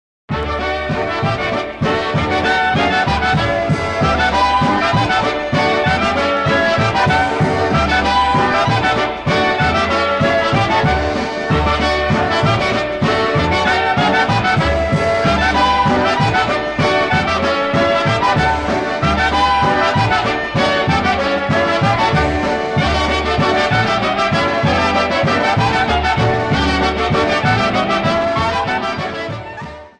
Media > Music > Polka, General